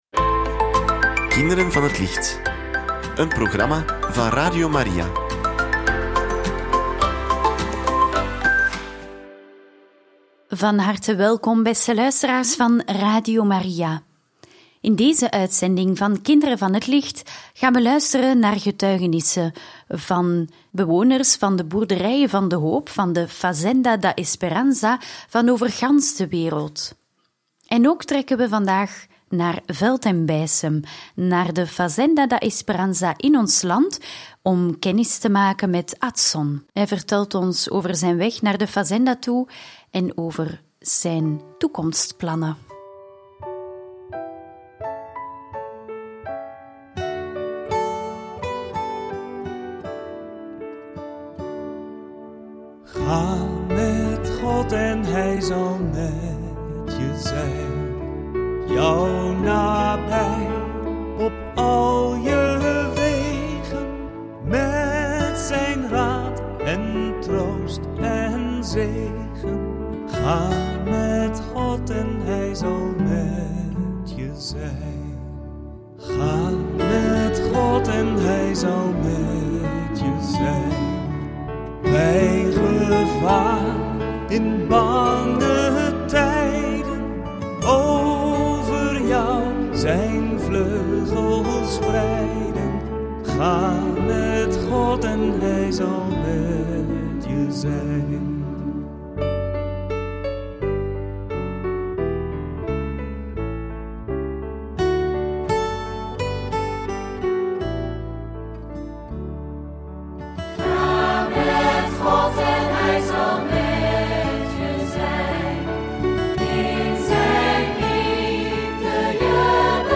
Getuigenissen van bewoners van de boerderijen van de Hoop en van leden van de familie van de Hoop!